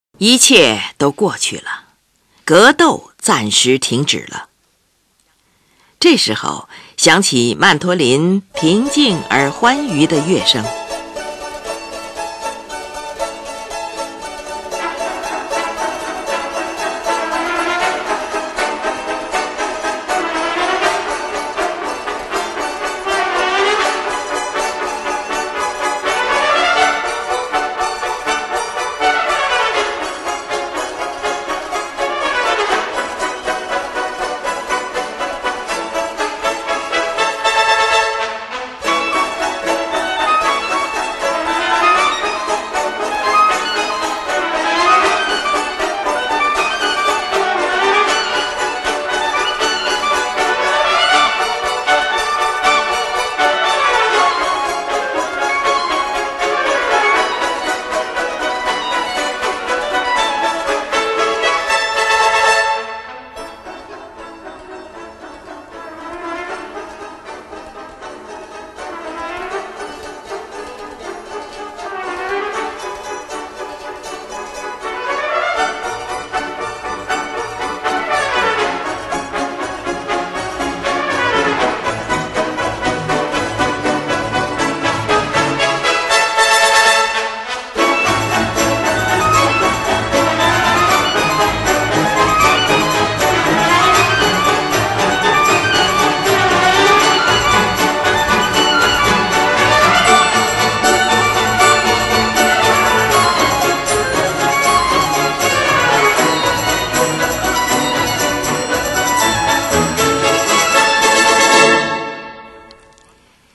这是曼陀铃平静而欢愉的乐声。
节日的舞蹈，是由四声和弦齐奏开始的。这一段音乐的形象单一，旋律轻快，不断重复。这描绘出生机勃勃的民间舞蹈形象。